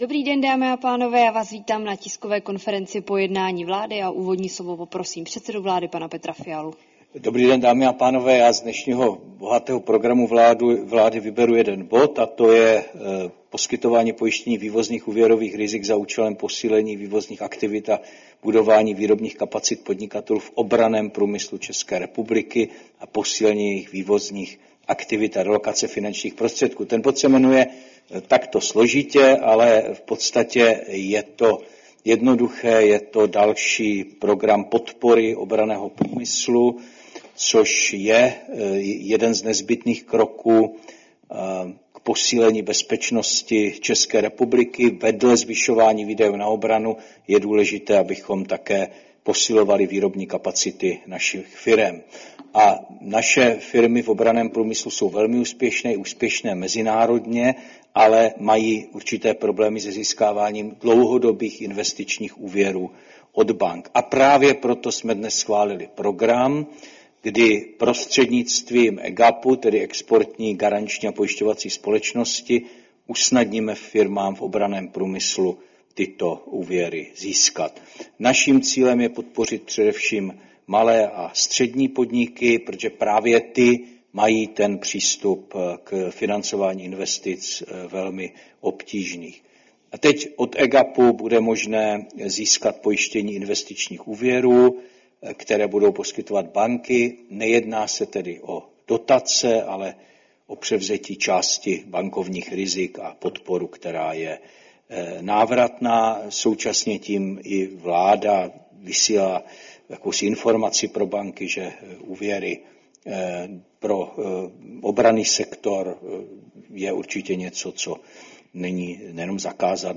Tisková konference po jednání vlády, 19. března 2025